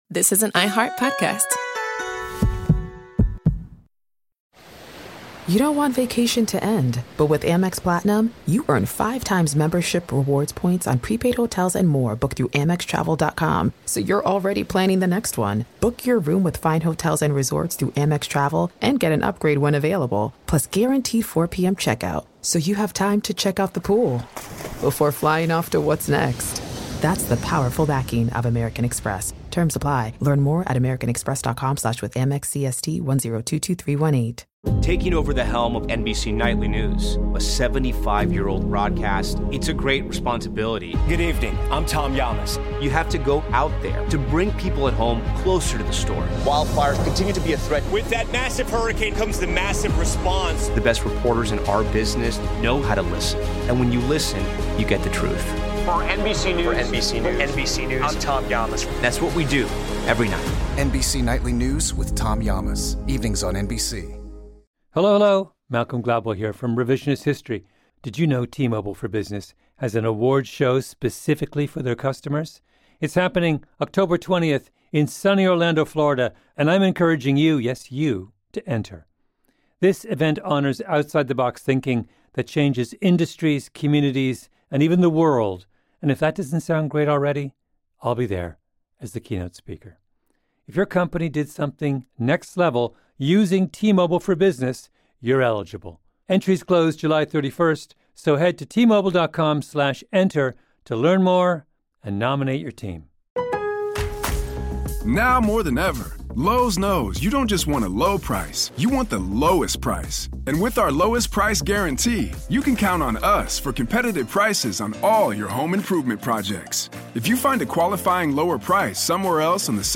On this episode of Our American Stories, on November 5, 2021, a funeral service was held for General Colin Powell at the Washington National Cathedral in Washington, D.C. He passed away at age 84 due to complications from COVID-19 while undergoing treatment for cancer.